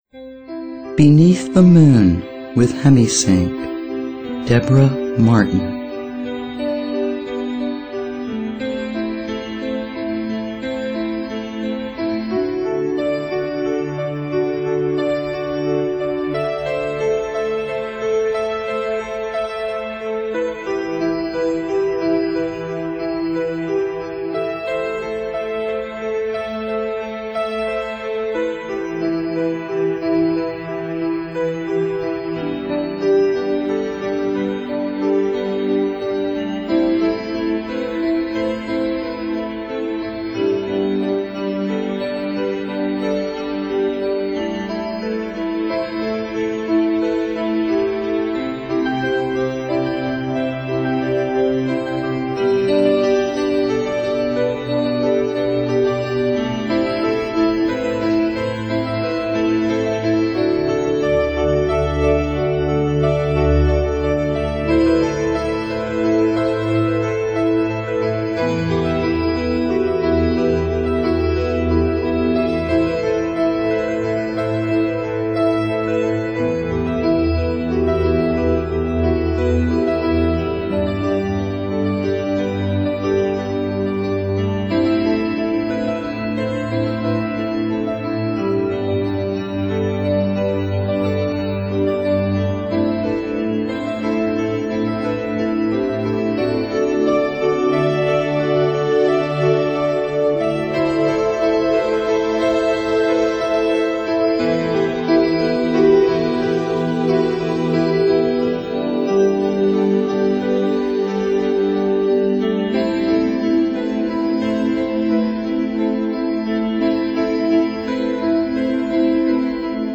Relaxace, Meditace, Relaxační a Meditační hudba
Verbální vedení: Neverbální